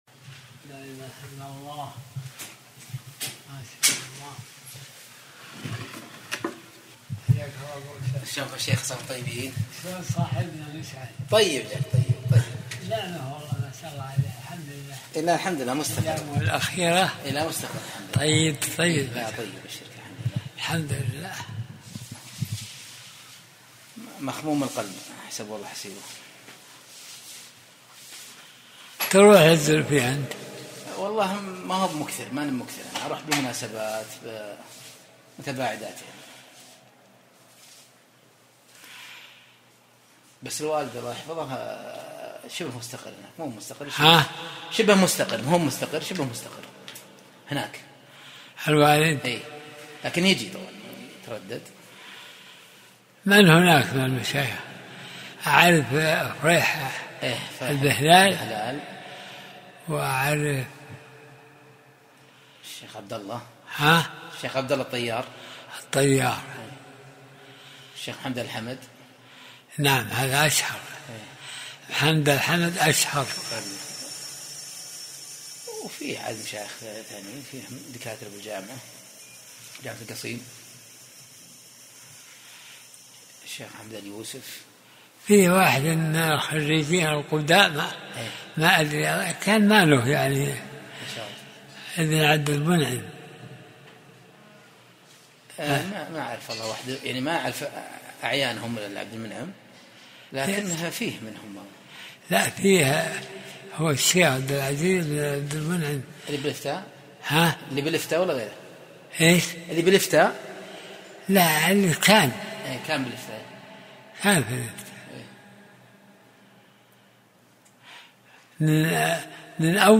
درس الأربعاء 55